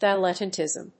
音節díl・et・tànt・ism 発音記号・読み方
/tiìzm(米国英語)/